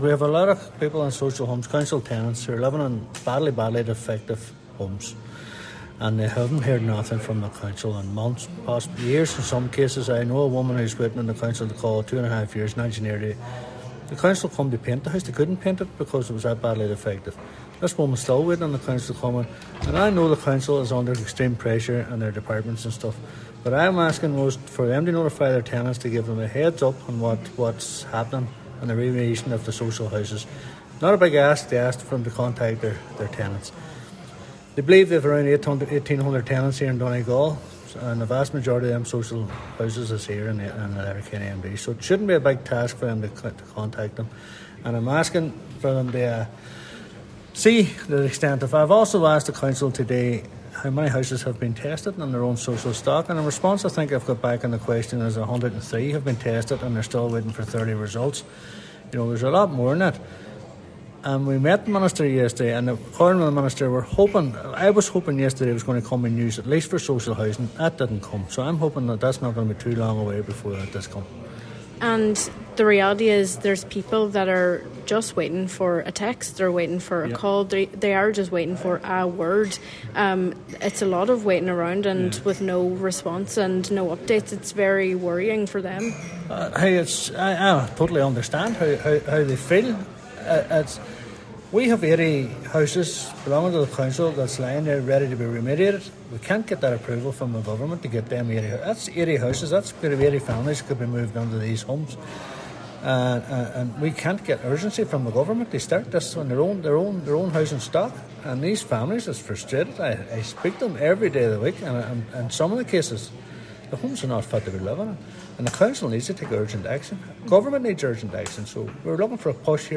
Cllr Devine says it’s frustrating that the council cannot begin remediation works until funding is secured: